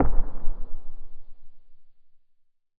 explosion_far_distant_03.wav